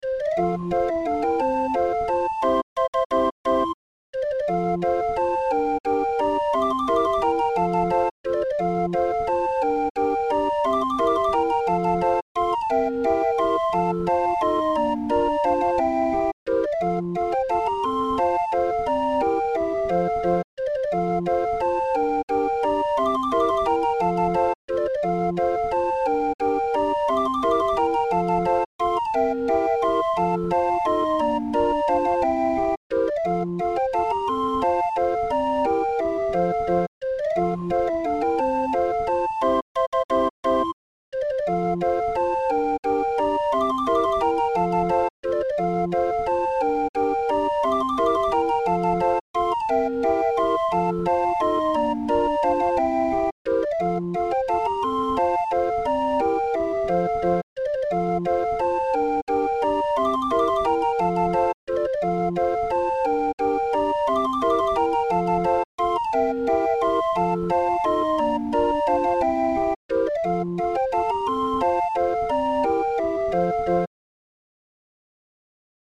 Musikrolle für 20-er Raffin